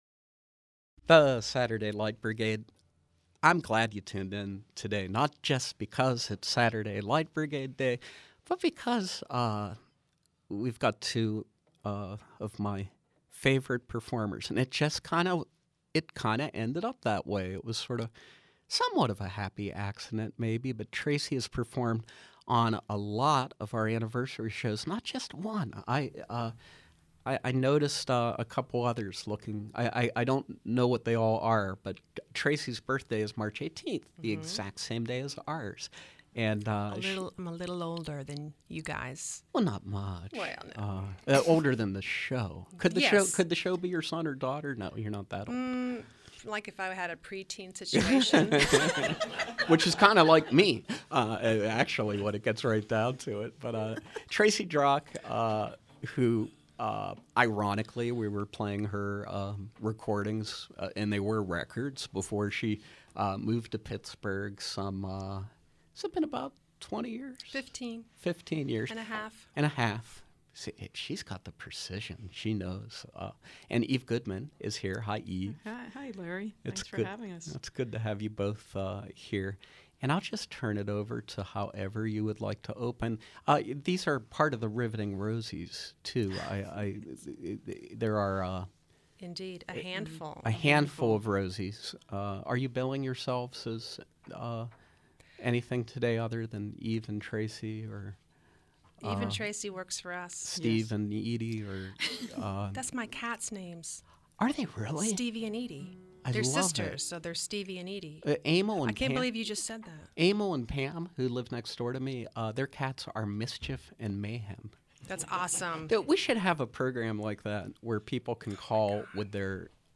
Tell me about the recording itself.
Live Music Live music